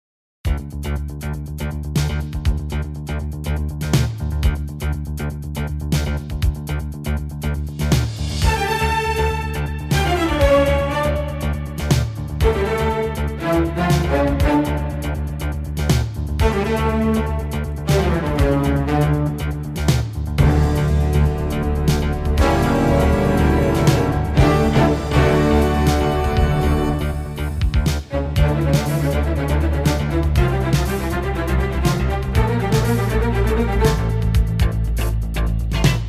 красивые
электронная музыка
спокойные
без слов
инструментальные
орган